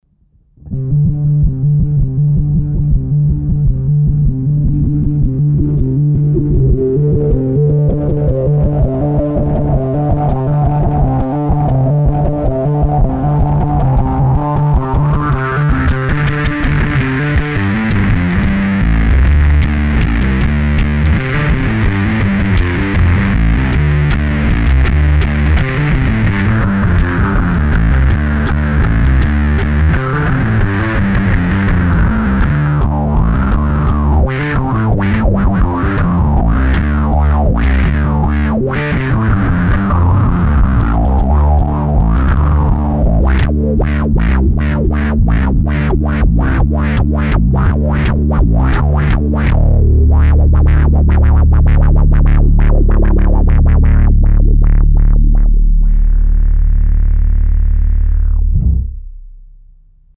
Použita byla baskytara Ernie Ball Music Man StingRay 5, a jinak je to nahráno přes pedál rovnou do zvukovky a jen normalizováno.
Fuzz